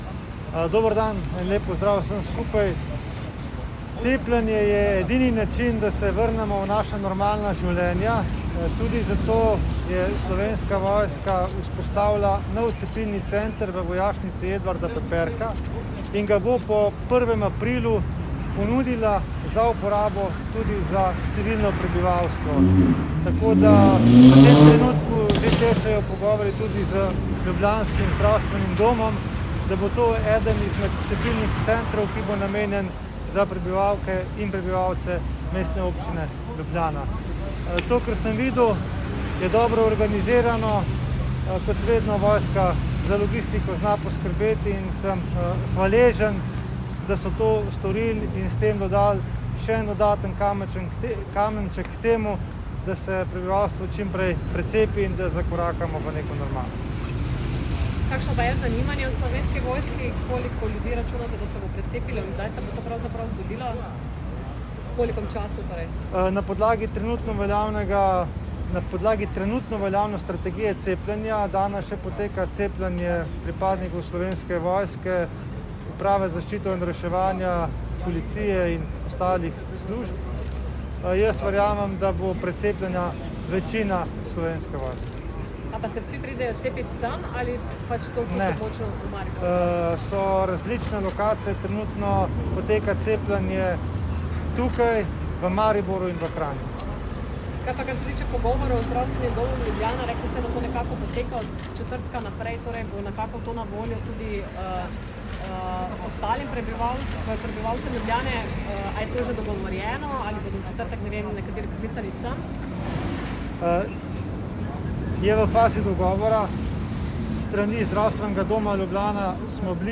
Zvočna posnetka izjav za medije (MP3):
minister za obrambo mag. Matej Tonin